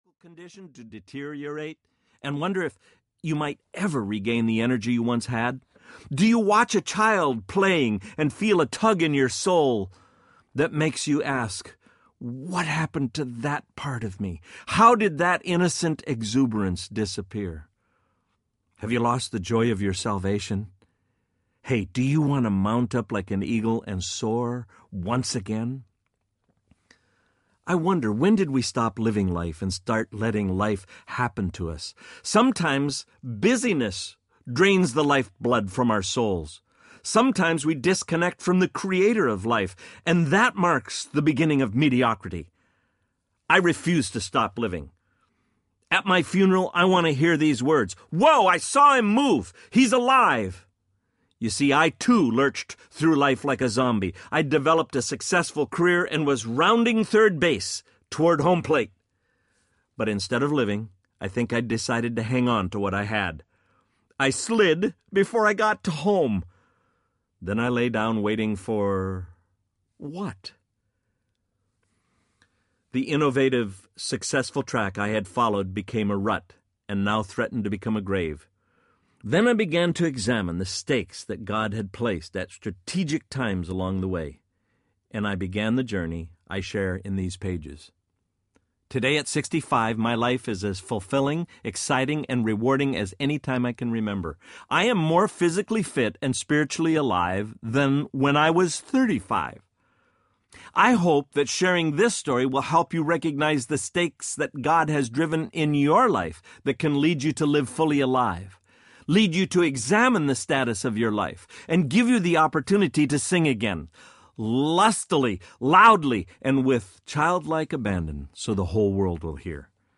Fully Alive Audiobook
6.8 Hrs. – Unabridged